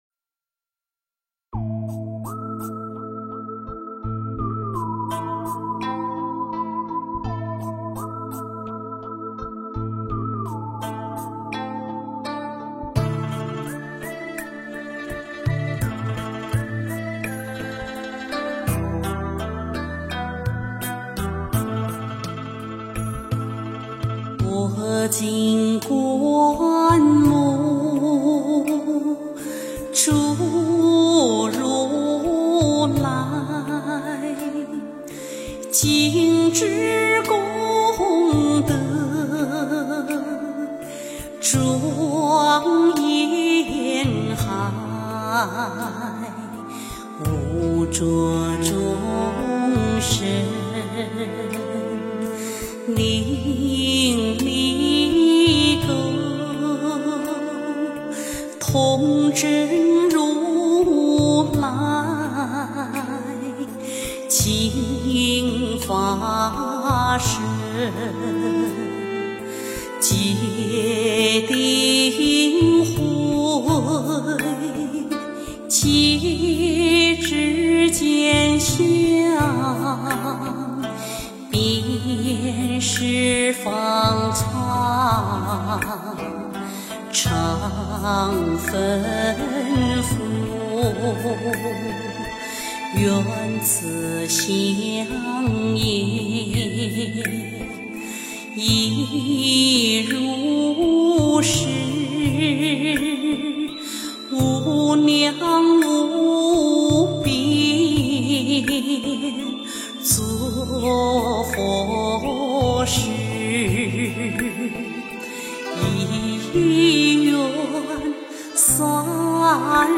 诵经
佛音 诵经 佛教音乐 返回列表 上一篇： 指月 下一篇： 原来退步是向前 相关文章 貧僧有話38說：我对生死的看法 貧僧有話38說：我对生死的看法--释星云...